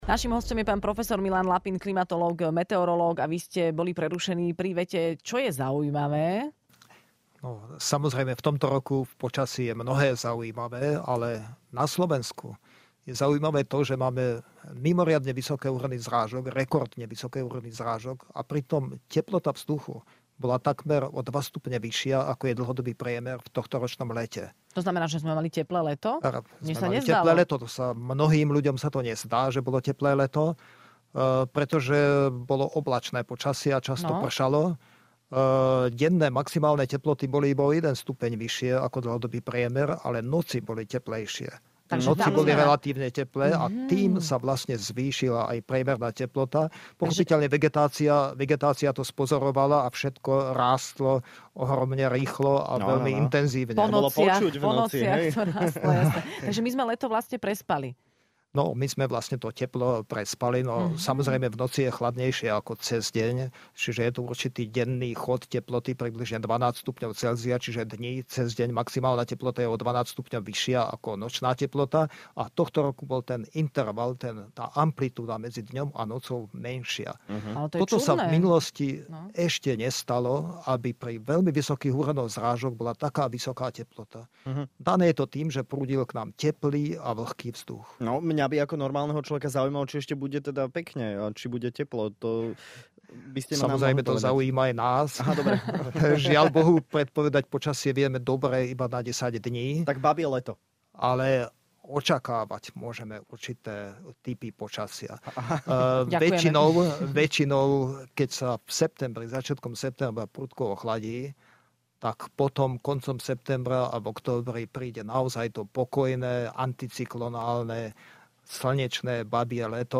Počasie si s nami stále robí čo chce, a tak sme si do štúdia zavolali klimatológa